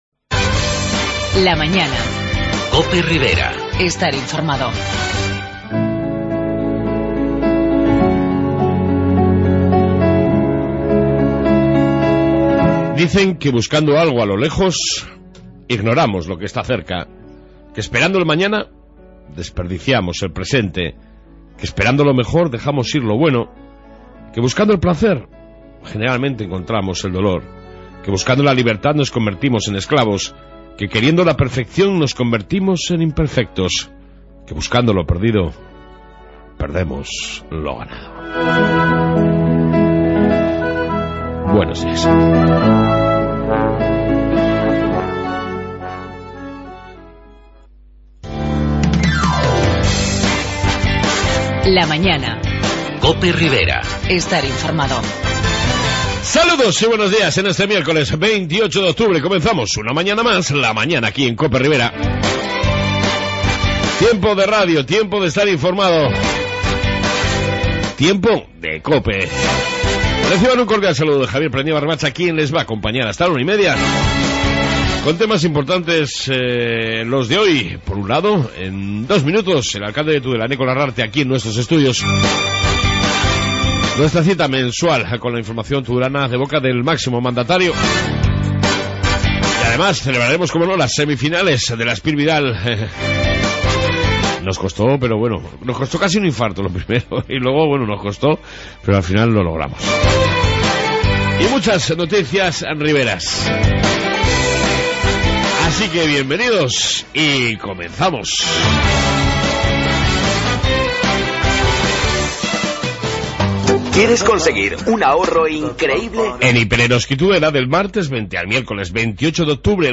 AUDIO: En esta 1 Parte Reflexión diaria y Amplia entrevista con el Alcalde de Tudela Eneko Larrarte